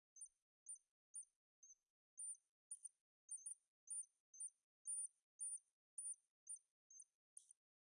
OWI " 恐慌的老鼠
描述：通过用塑料笔吹出墨水来创建。
Tag: 危险 动物 OWI 愚蠢